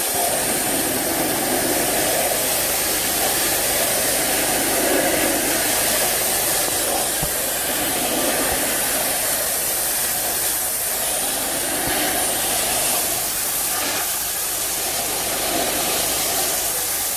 Hose_Audio_C.wav